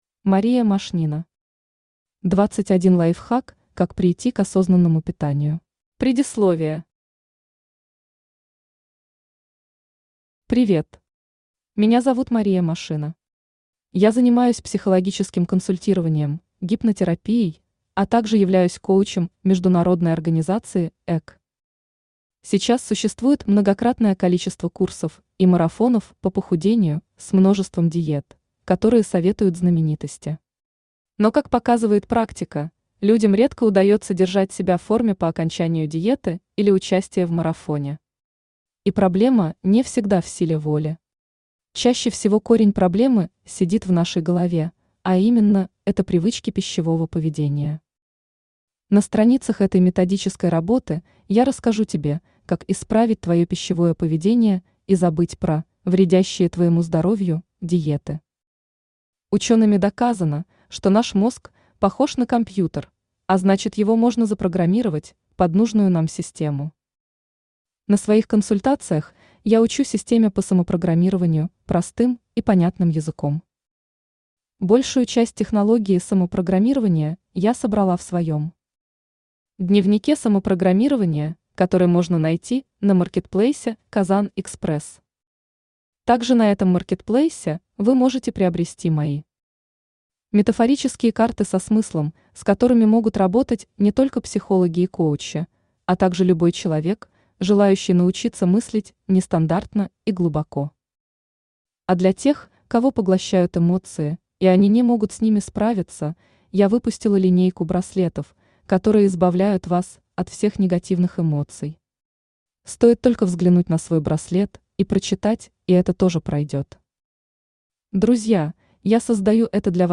Аудиокнига 21 лайфхак как прийти к осознанному питанию | Библиотека аудиокниг
Aудиокнига 21 лайфхак как прийти к осознанному питанию Автор Мария Владимировна Машнина Читает аудиокнигу Авточтец ЛитРес.